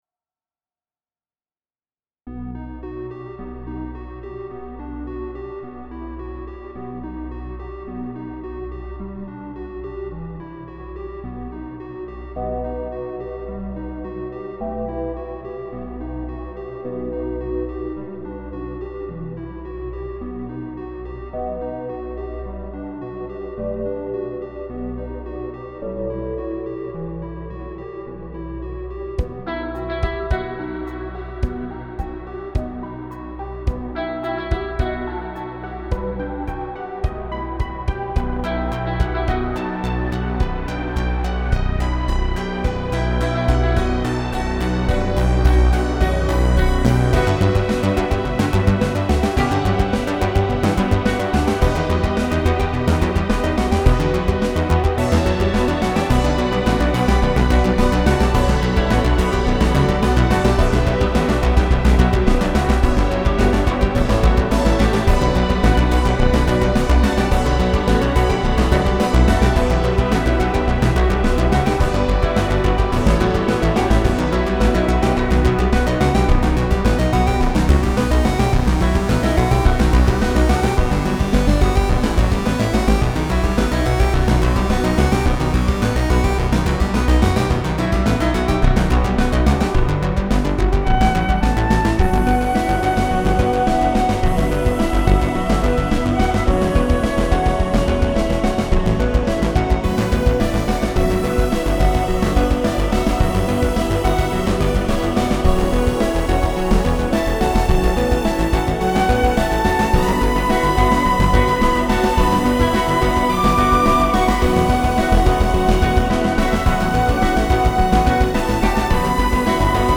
Big Pokemon Mystery Dungeon influences near the end tbh and I'm quite liking it so far.